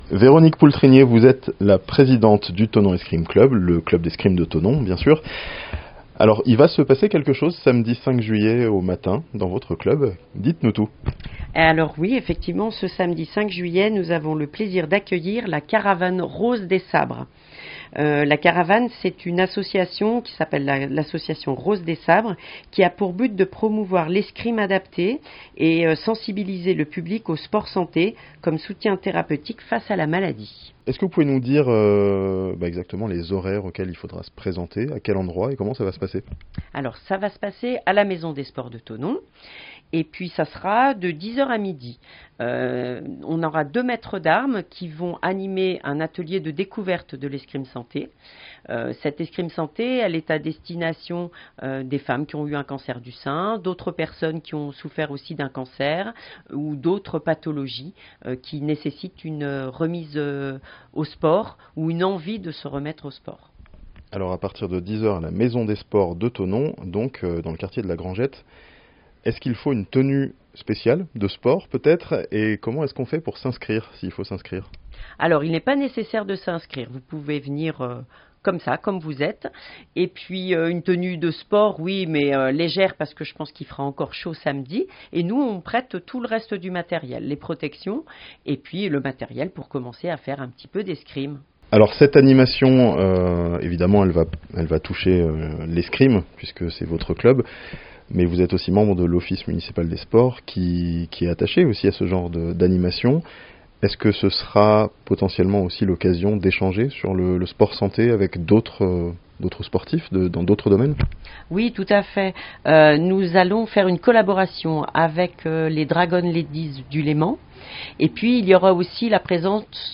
Au Thonon Escrime Club, la caravane "Rose des Sabres" inaugurera un nouveau créneau de sport santé ce samedi 5 juillet (interview)